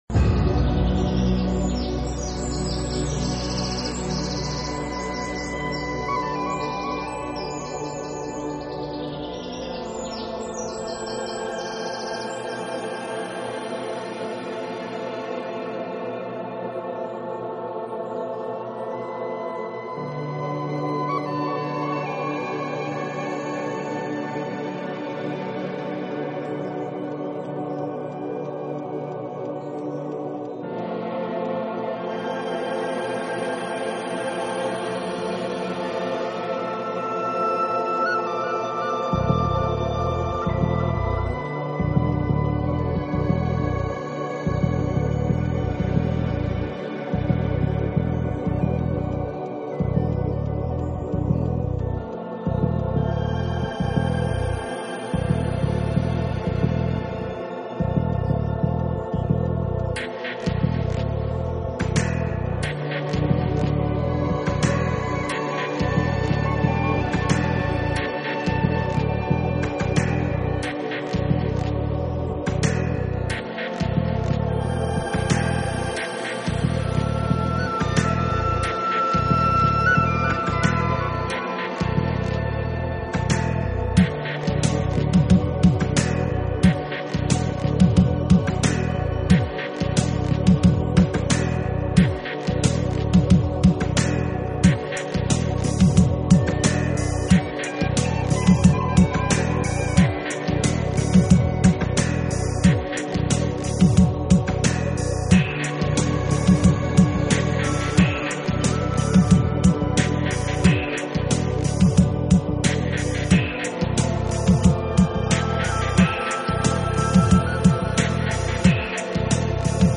【纯音乐】
Genre: New Age